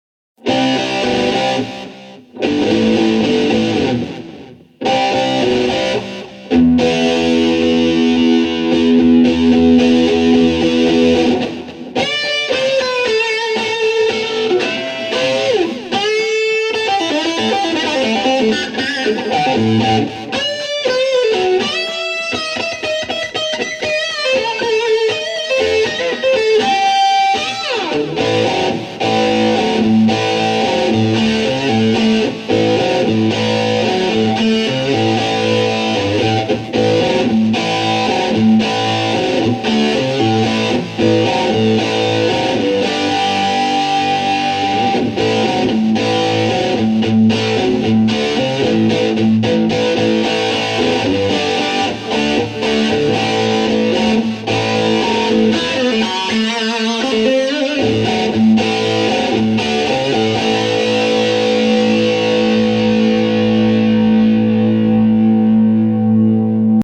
et là on arrive à un vrai gros gros son monstre (probablement déjà trop gros et trop dominant pour un mix normale) :
gratte2-fullboost.mp3